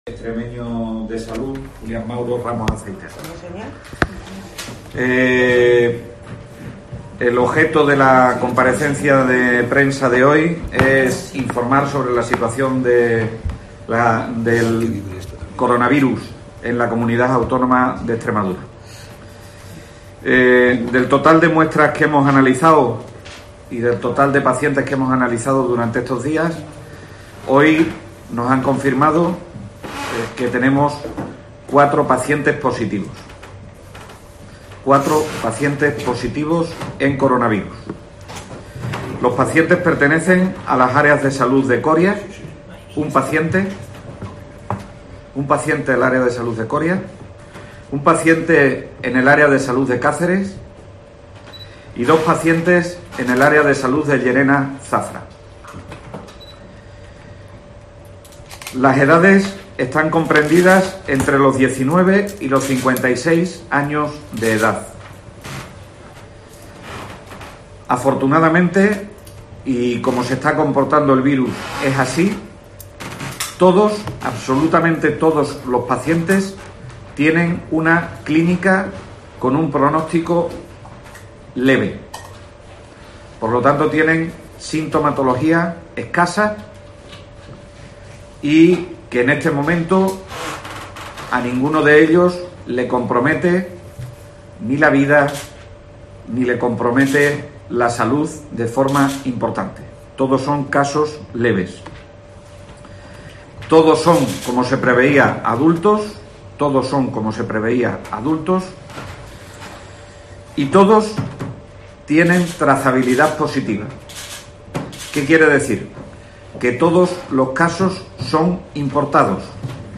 AUDIO: Lo ha anunciado en esta rueda de prensa en la que ha quierido tranquilizar a la población